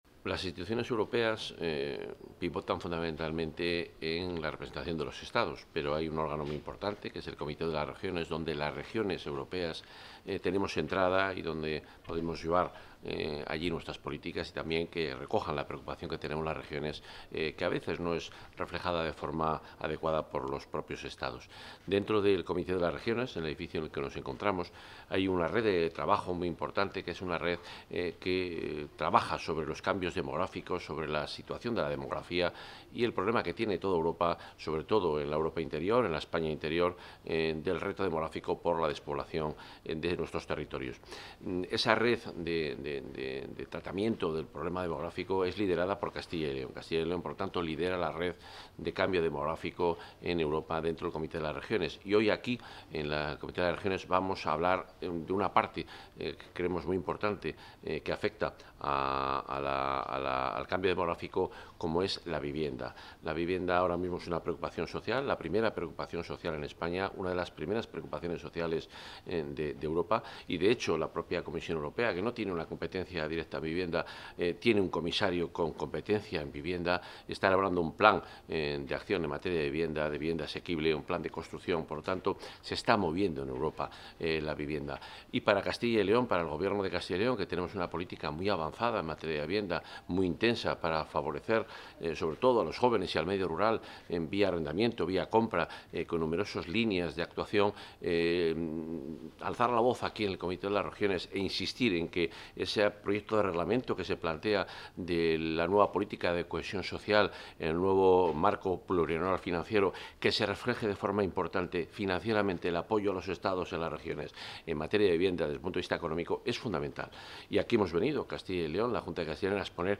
Intervención del consejero.
En el marco de la jornada 'Demographic change: the impact on housing', celebrada en el Comité de las Regiones en Bruselas y organizada por la Red DCRN (Demographic Change Regions Network), que lidera Castilla y León, el consejero de Medio Ambiente, Vivienda y Ordenación del Territorio de la Junta de Castilla y León, Juan Carlos Suárez-Quiñones, ha defendido la vivienda rural como herramienta esencial para afrontar los retos del envejecimiento, el despoblamiento y la falta de oportunidades para los jóvenes en las zonas rurales europeas.